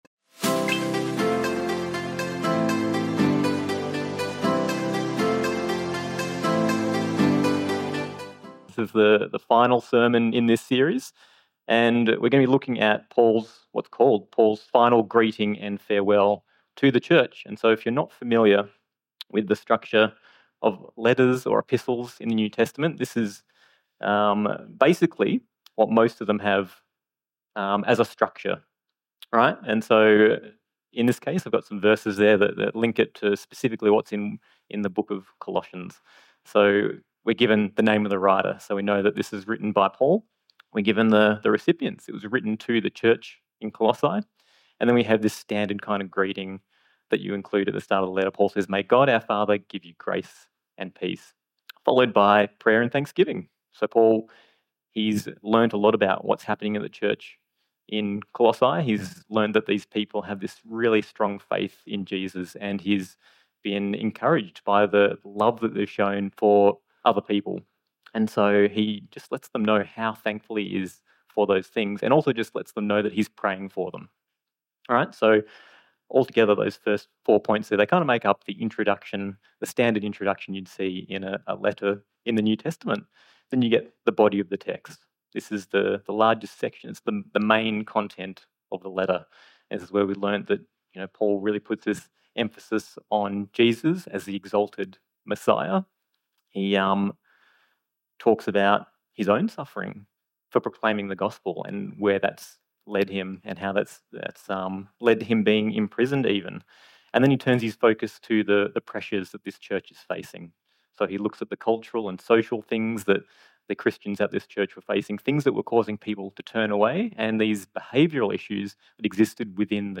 In this final message we read Paul's parting words to the Colossians - reminding them again that their faith in Jesus Christ should shape every part of their life.